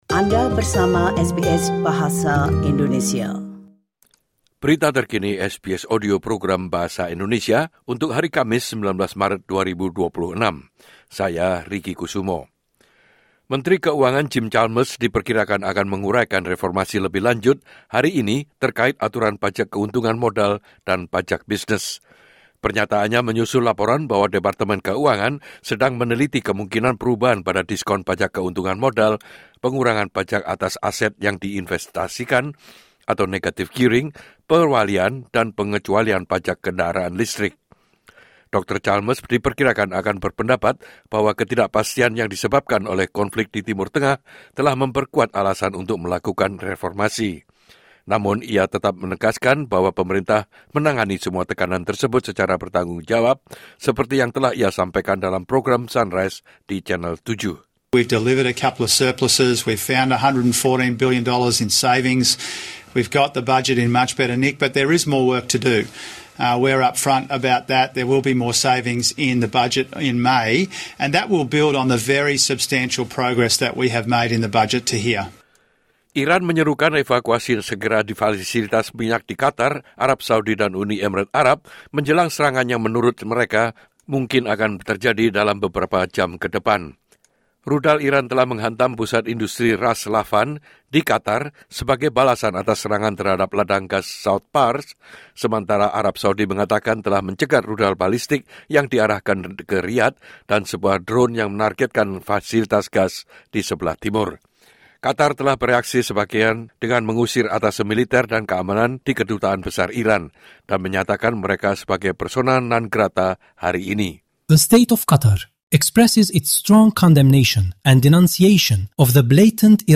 Latest News SBS Audio Program Bahasa Indonesia - Thursday 19 March 2026